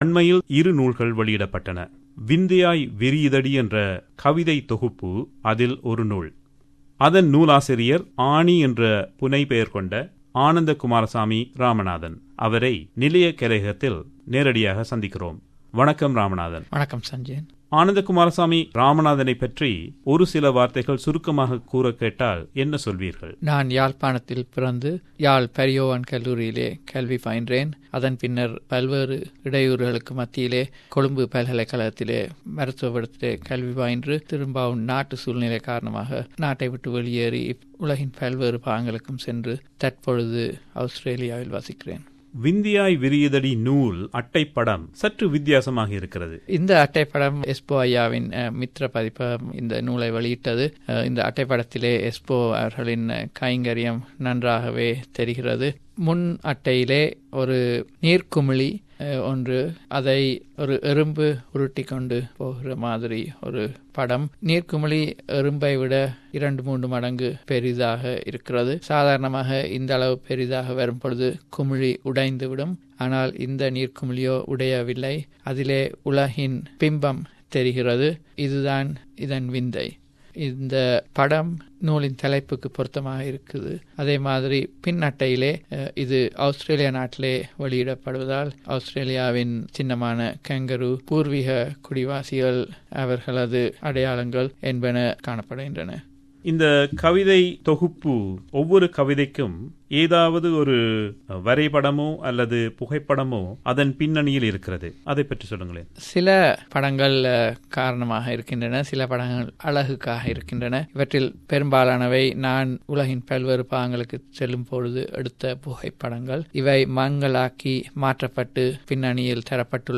ஒரு சந்திப்பு.